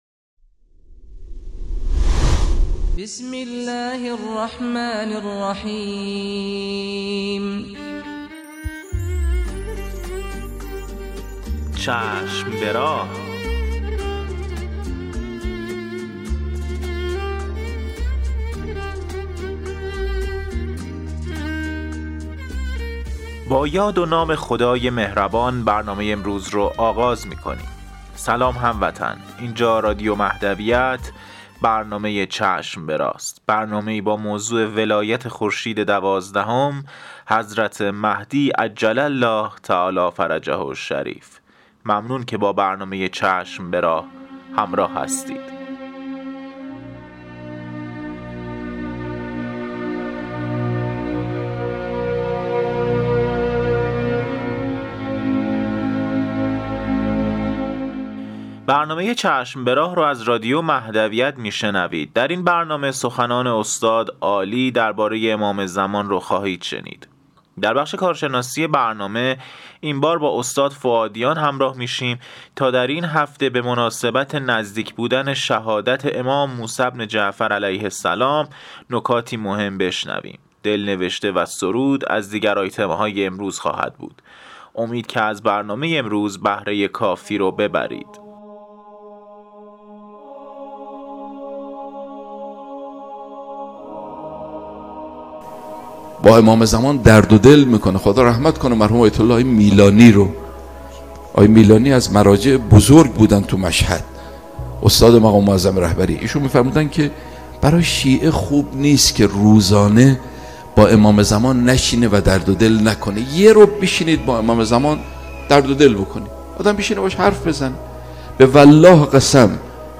قسمت صد و شصت و چهارم مجله رادیویی چشم به راه که با همت روابط عمومی بنیاد فرهنگی حضرت مهدی موعود(عج) تهیه و تولید شده است، منتشر شد.